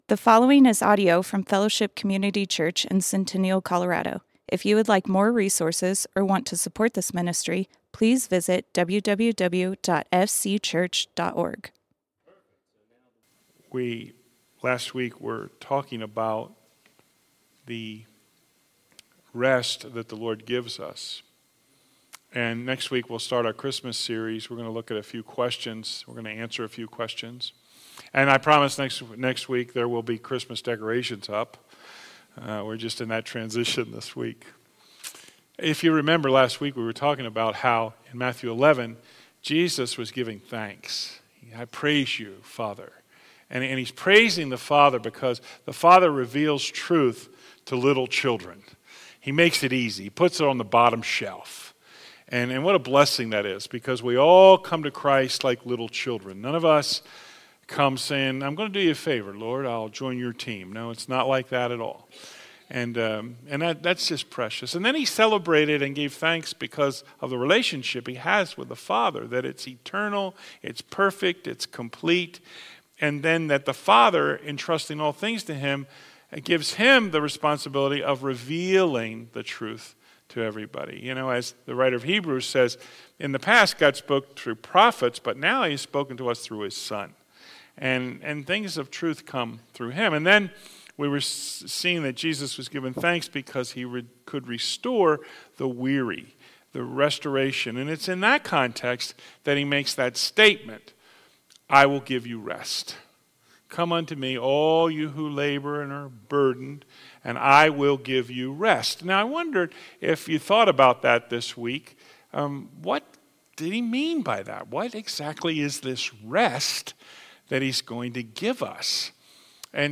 Fellowship Community Church - Sermons Sabbath Sunday Play Episode Pause Episode Mute/Unmute Episode Rewind 10 Seconds 1x Fast Forward 30 seconds 00:00 / 23:30 Subscribe Share RSS Feed Share Link Embed